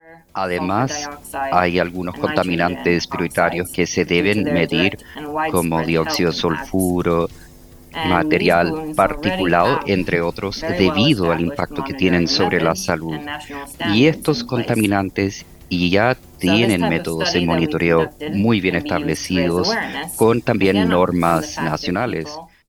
entrevista-en-ingles-cuna-3.mp3